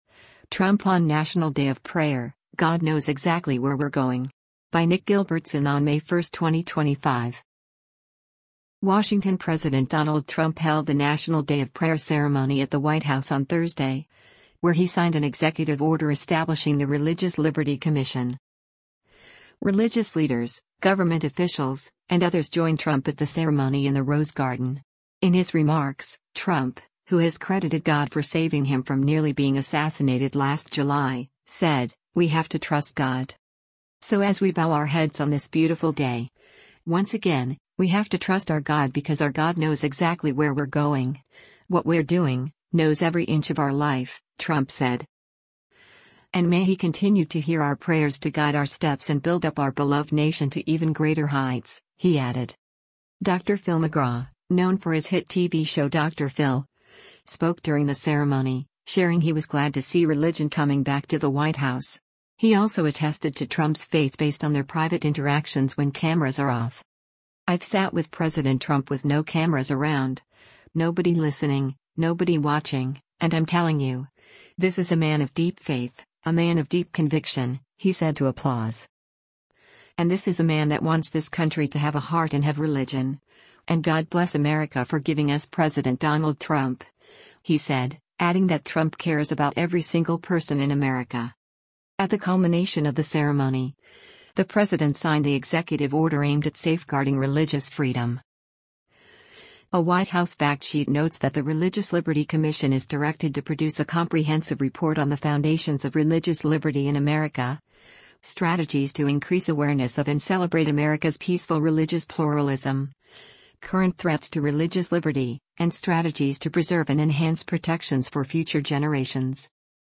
WASHINGTON–President Donald Trump held a National Day of Prayer ceremony at the White House on Thursday, where he signed an executive order establishing the “Religious Liberty Commission.”
Religious leaders, government officials, and others joined Trump at the ceremony in the Rose Garden.